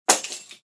Ключи упали на пол звуковая версия 2